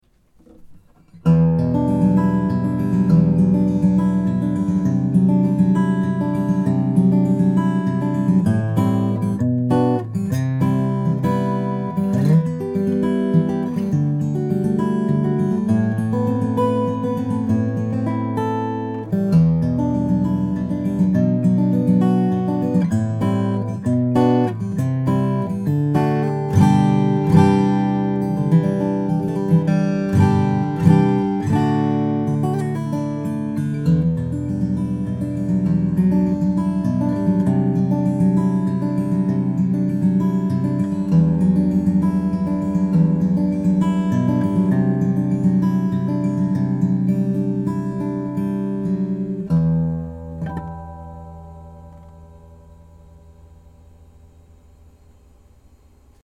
This Brazilian/Adirondack sweetheart has just the right mix of rich, warm tone, clarity and small body… Read more »